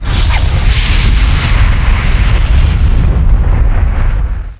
JETSPUTR.WAV